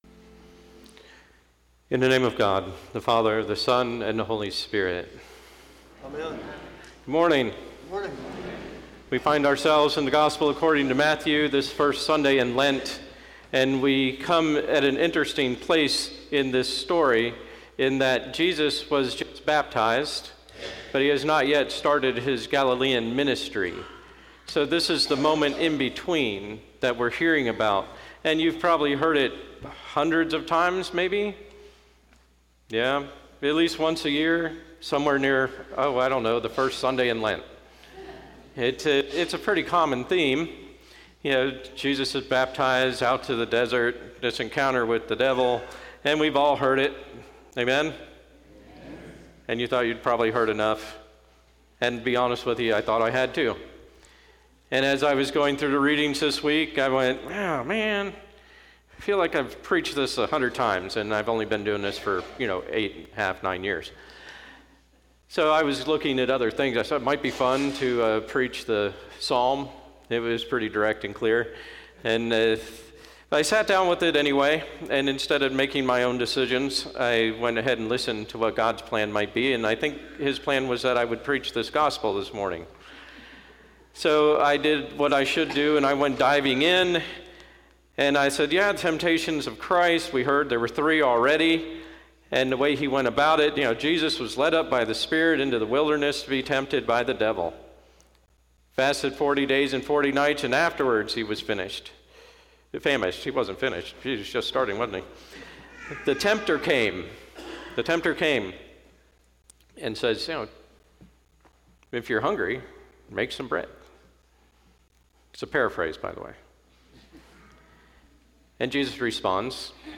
Sermon 3.1.20 First Sunday in Lent